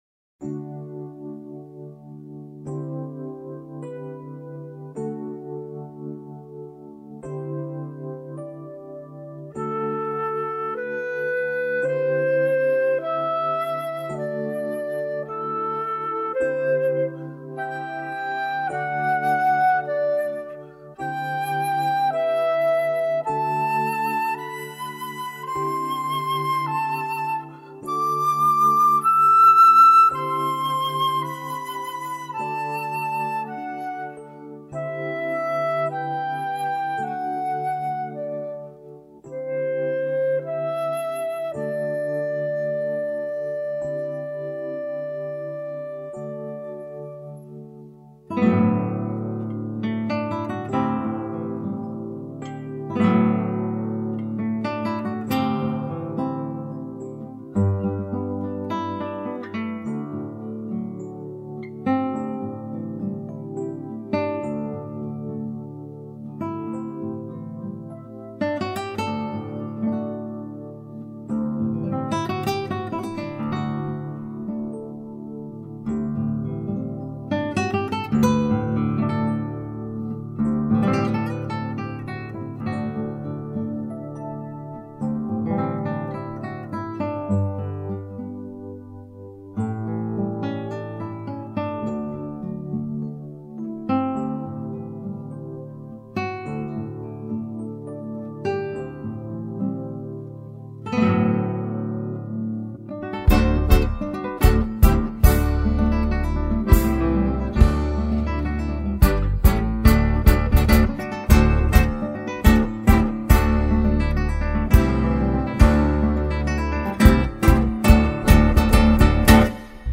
334   04:49:00   Faixa:     Instrumental
Acoordeon
Flauta
Bateria
Percussão